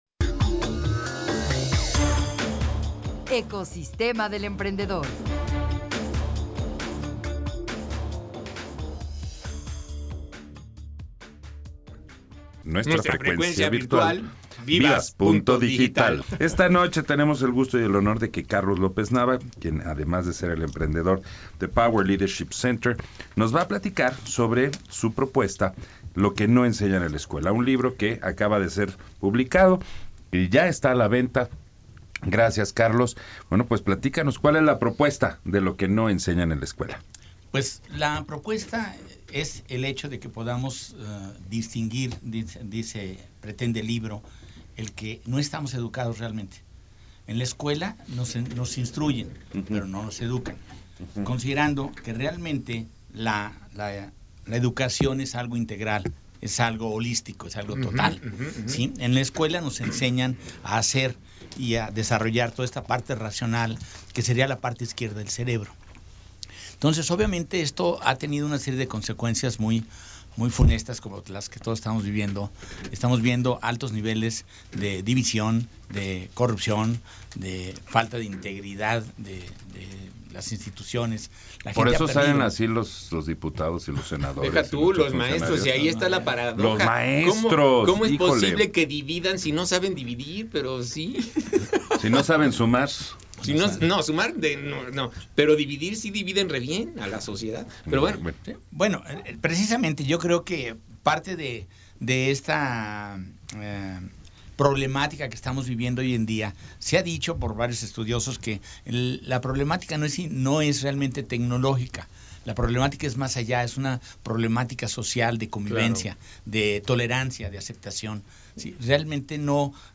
Entrevista en Radio RED Empresarial | Power Leadership Center